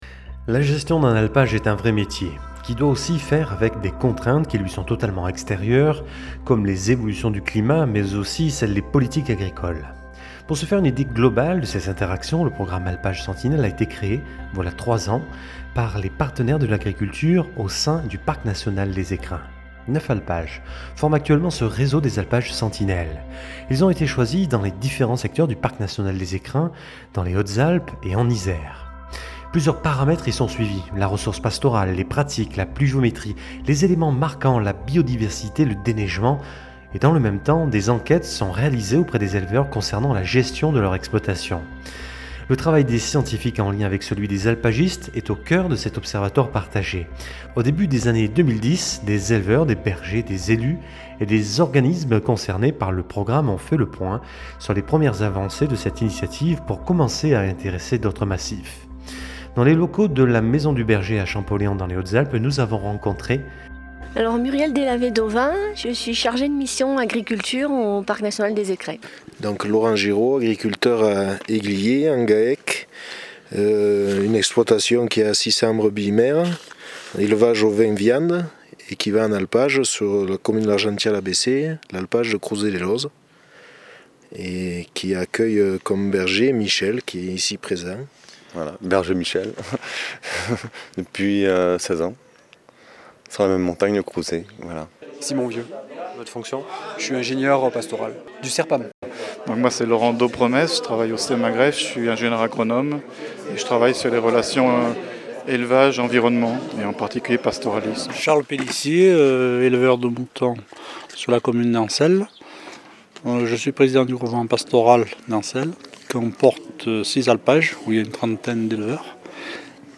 Reportage sonore sur les "Alpages sentinelles"
Ils sont éleveur, berger, chercheur, pastoraliste, agent du Parc national... partenaires du programme Alpages sentinelles dans le Parc national des Écrins.